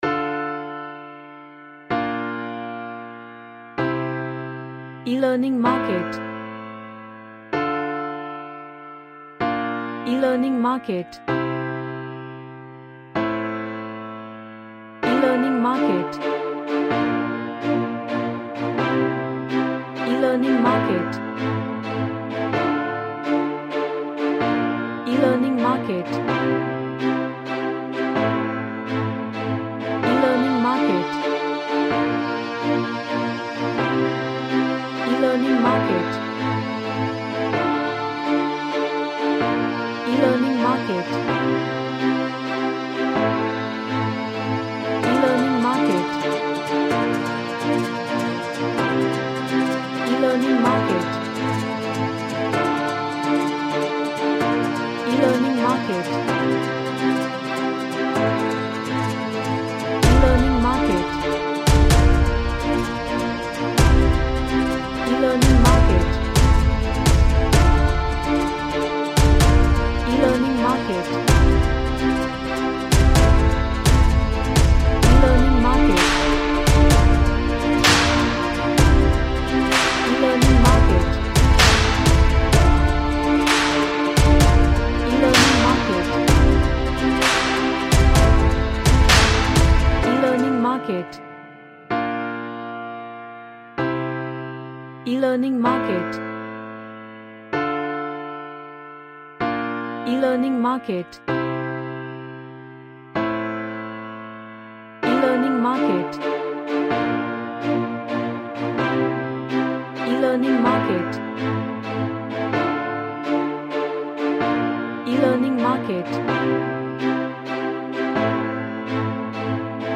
A gentle orchestral vibed track
Gentle / Light